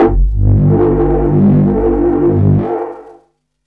bass m 1.107.wav